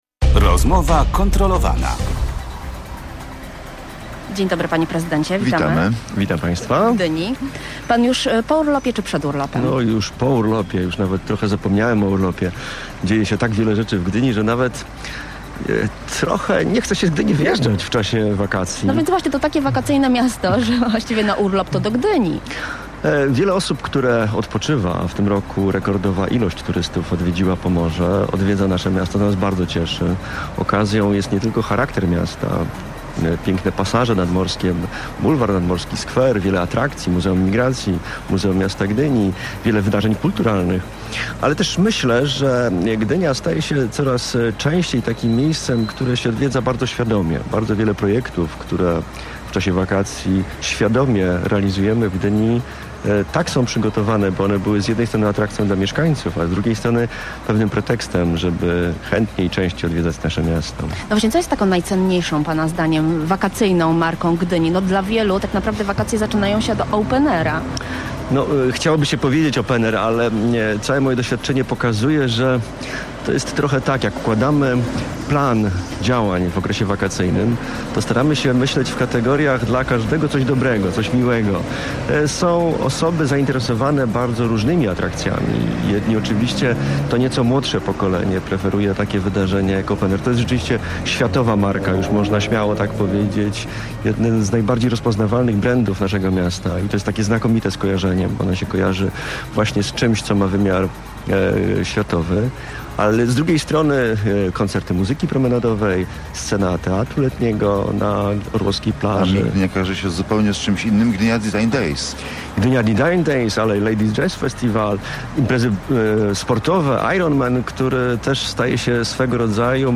Nie mam wątpliwości. że kiedyś turyści wylądują na gdyńskim lotnisku - mówi w Radiu Gdańsk Wojciech Szczurek prezydent Gdyni i